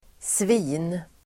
Ladda ner uttalet
Uttal: [svi:n]
svin.mp3